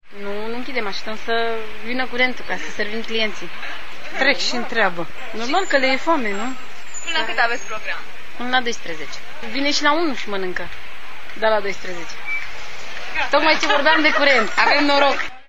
Cel mai tarziu s-a reluat furnizarea curentului electric in zona Central. In timp ce unele vanzatoare se chinuiau sa inchida casa pe intuneric, altele asteptau rabdatoare sa se aprinda lumina: